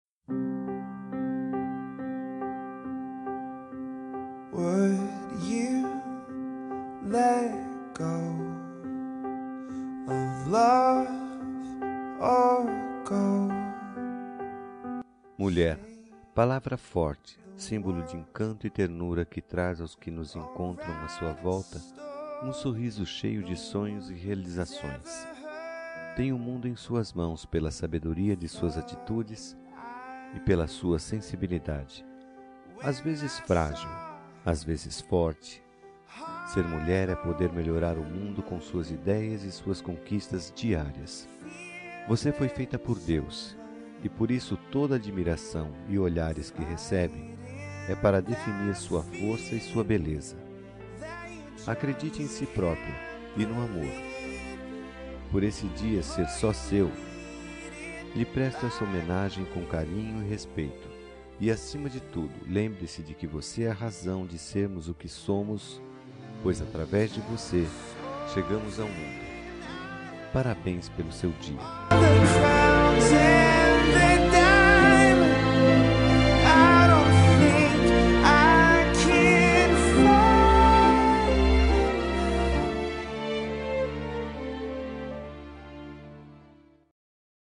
Dia das Mulheres Neutra – Voz Masculina – Cód: 5274